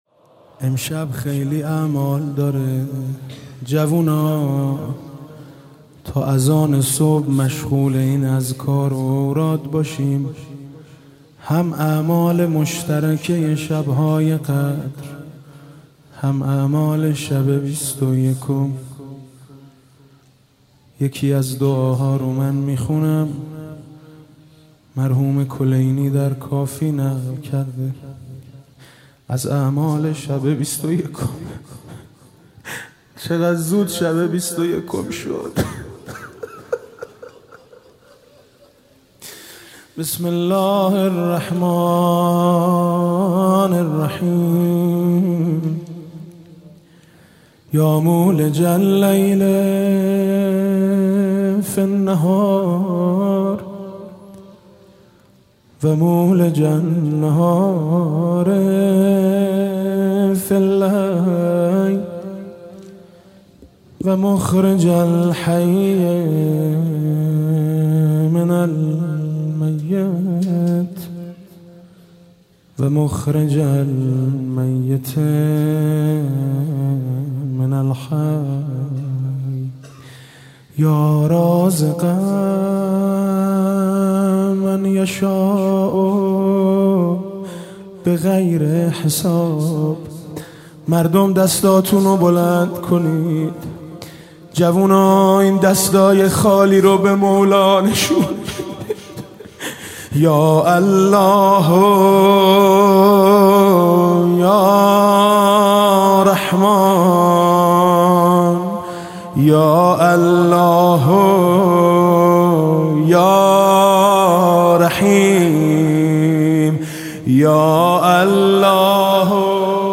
دعا